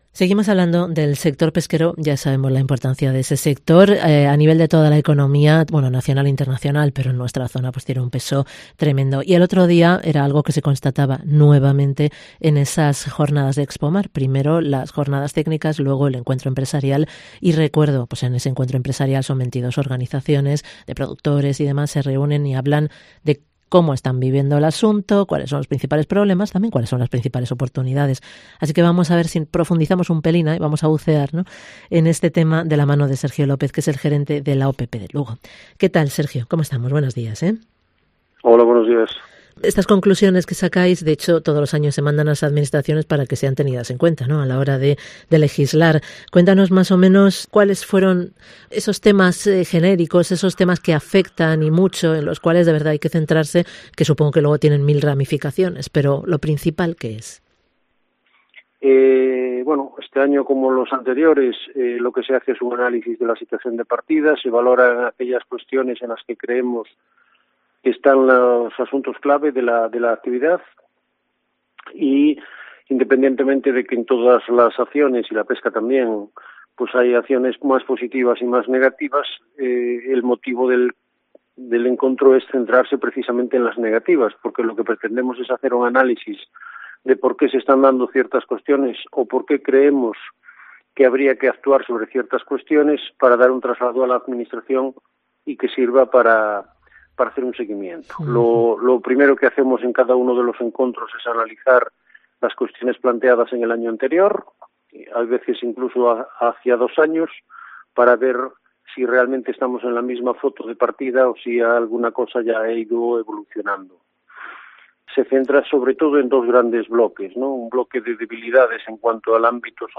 nos da las claves en esta entrevista.